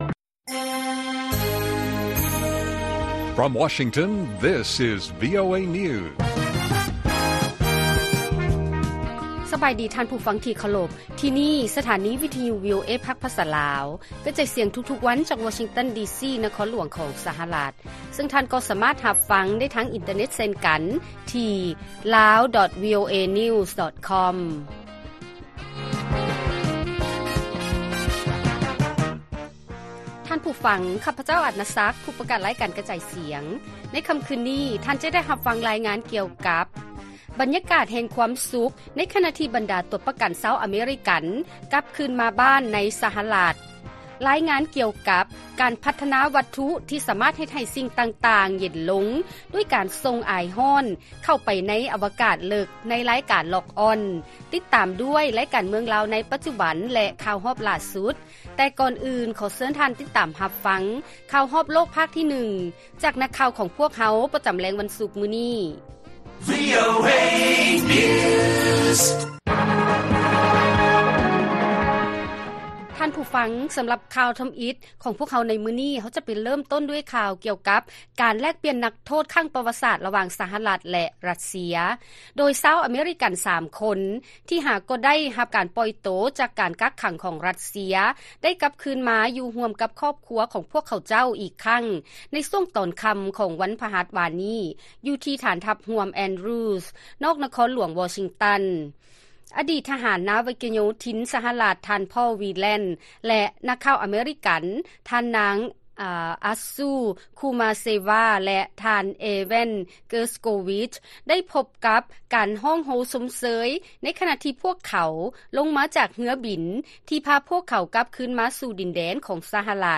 ລາຍການກະຈາຍສຽງຂອງວີໂອເອລາວ: ຊາວອາເມຣິກັນ ທີ່ໄດ້ຮັບການປ່ອຍໂຕຈາກການແລກປ່ຽນນັກໂທດຄັ້ງປະຫວັດສາດ ເດີນທາງມາຮອດ ສະຫະລັດ ແລ້ວ